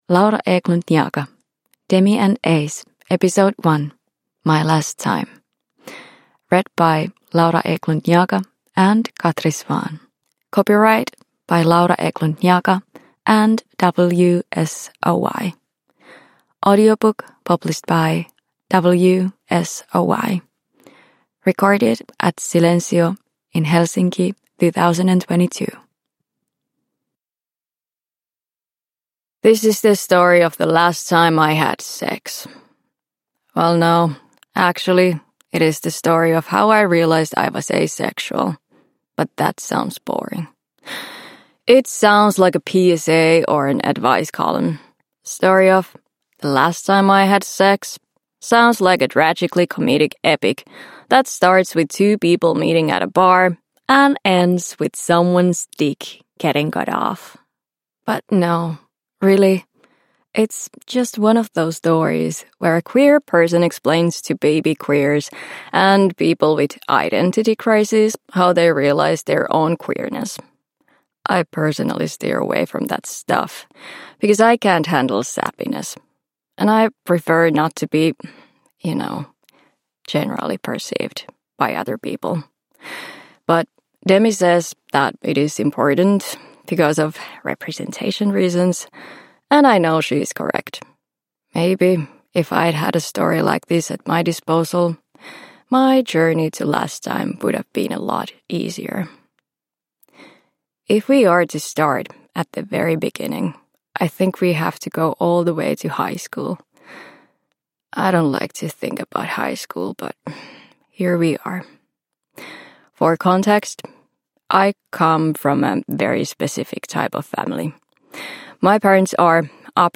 Demi and Ace 1: My Last Time – Ljudbok – Laddas ner